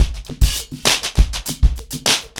PrintOuts-100BPM.5.wav